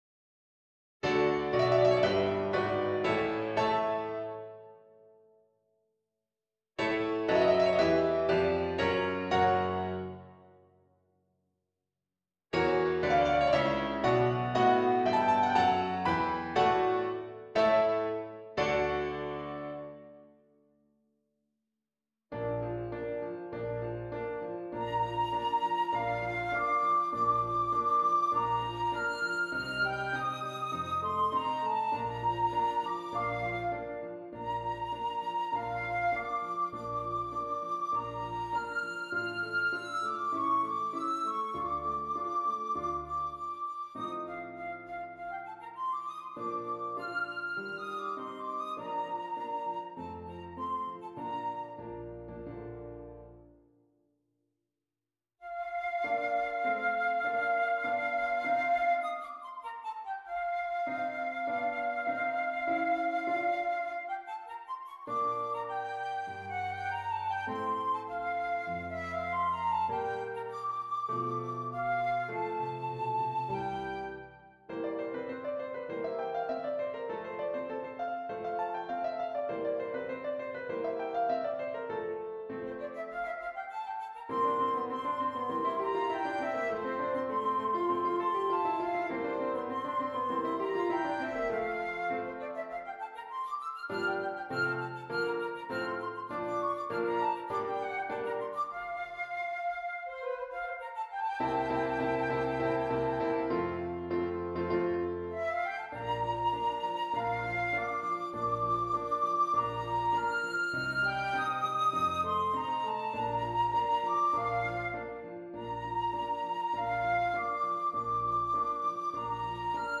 Flute and Keyboard
This version has been lowered in pitch.